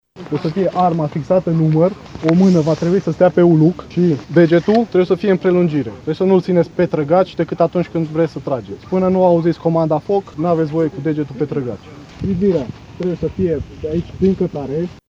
Cupa Presei la Tir, ediția 2016, s-a desfășurat astăzi la Poligonul de la Sângeorgiu de Mureș.
Pentru buna desfășurare a competiției, sesiunea de tir a început cu intructajul: